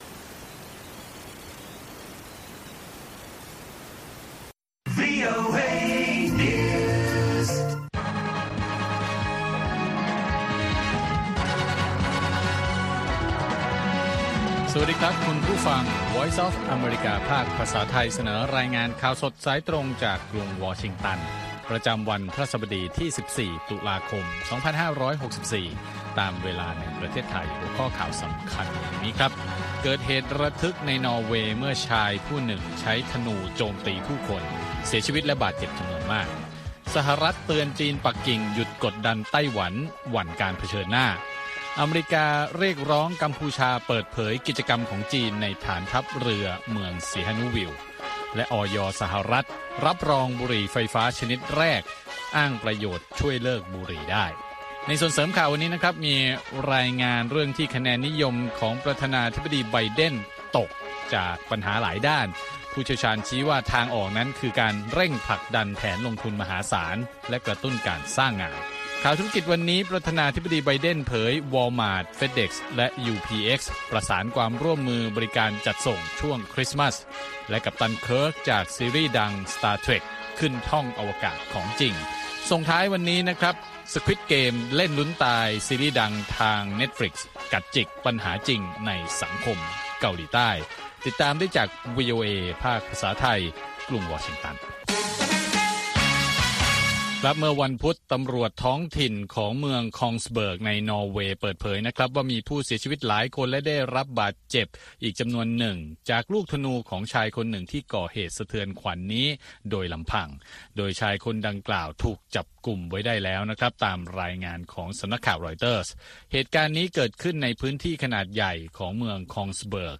ข่าวสดสายตรงจากวีโอเอ ภาคภาษาไทย 6:30 – 7:00 น. ประจำวันพฤหัสบดีที่ 14 ตุลาคม 2564 ตามเวลาในประเทศไทย